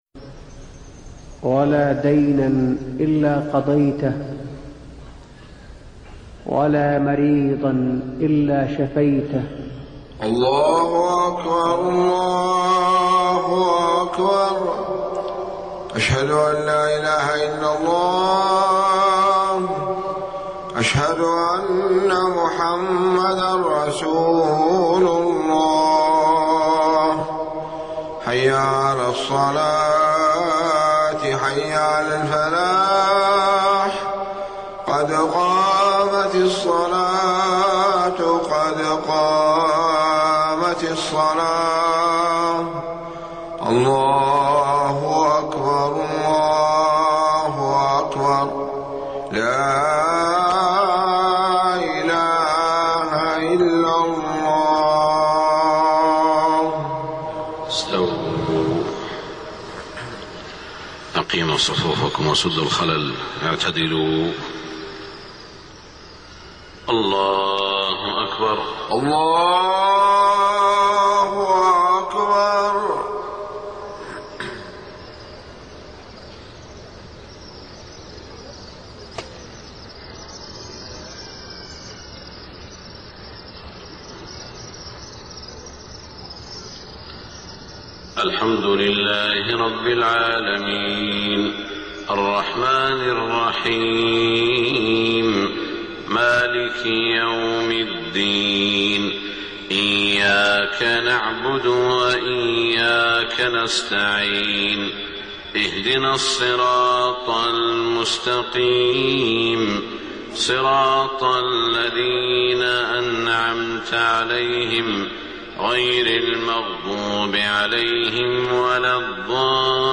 صلاة الفجر 8-1-1428هـ خواتيم سورة التوبة 115-129 > 1428 🕋 > الفروض - تلاوات الحرمين